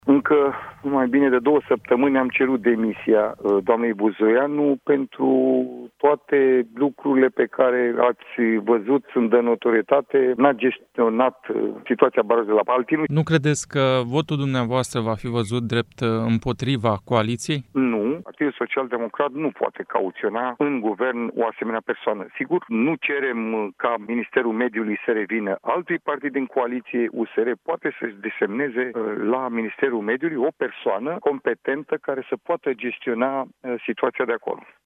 Liderul senatorilor PSD, Daniel Zamfir, a explicat la Europa FM de ce social-democrații vor acorda votul de neîncredere la adresa ministrei Mediului.